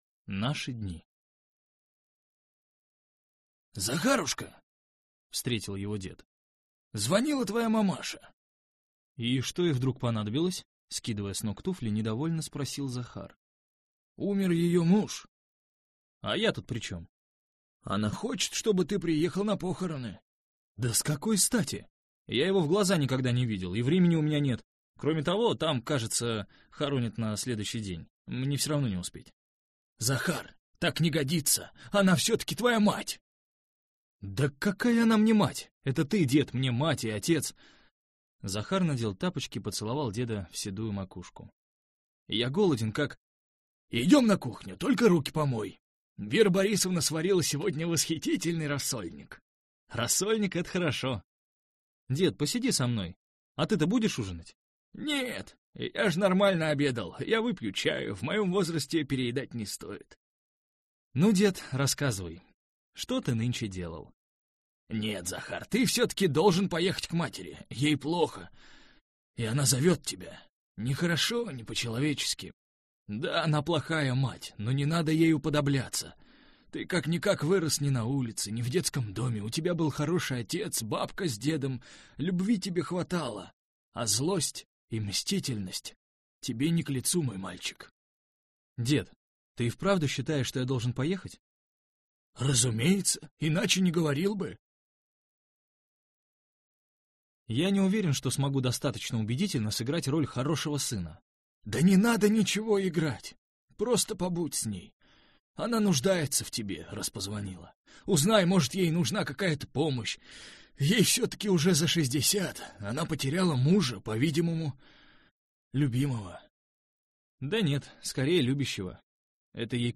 Аудиокнига Интеллигент и две Риты | Библиотека аудиокниг